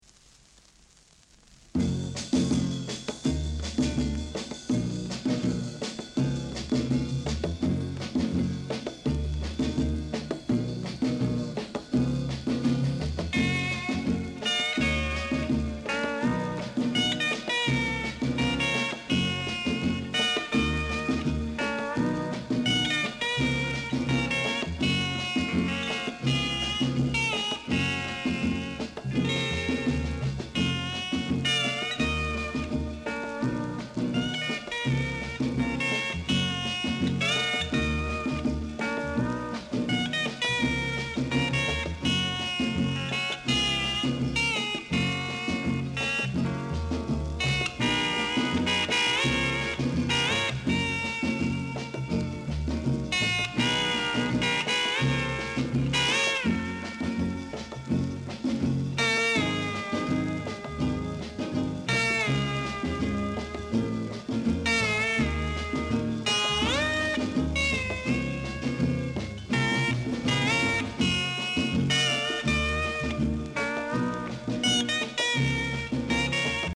Jazz Inst
Mega rare! great jazz inst w-sider!